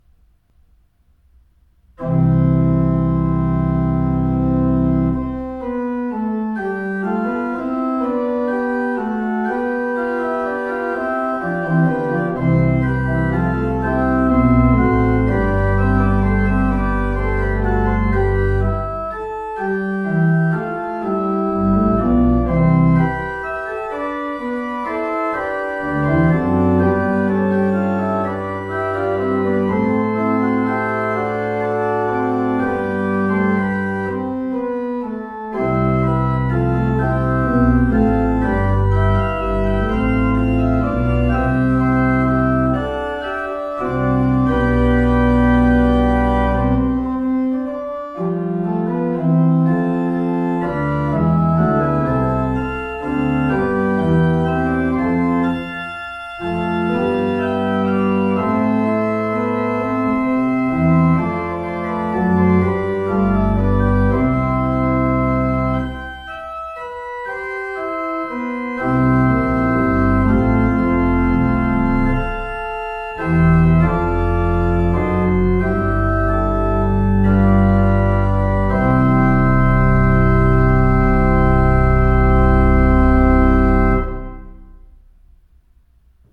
Gottesdienst vom Sonntag Misericoridas Domini nachhören
Orgelmusik: Johann Georg Herzog - Gelobt sei Gott im höchsten Thron (2 MB) Halleluja, Evangelium & Glaubensbekenntnis (5 MB) Lied: Lob Gott getrost mit Singen (3 MB) Predigt (27 MB) Gebet & Segen (1 MB)